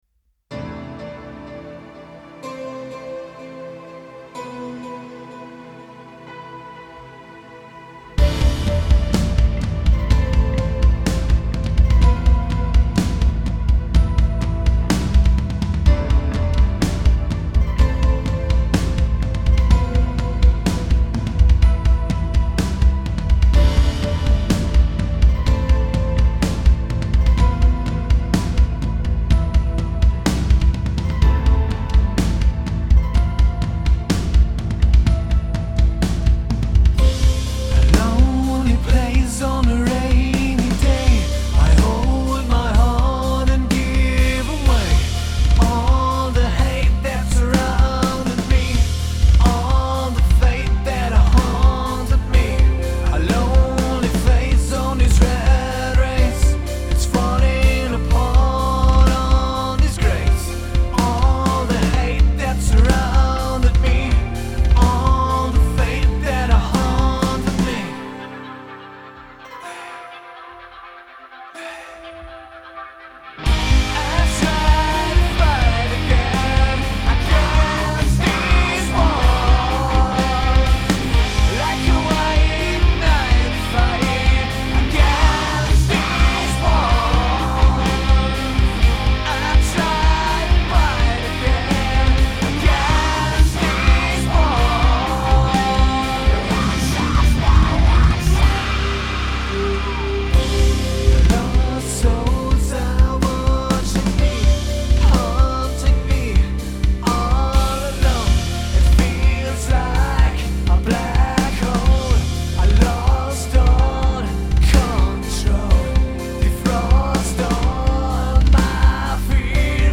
Alter Song neu aufgenommen